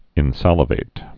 (ĭn-sălə-vāt)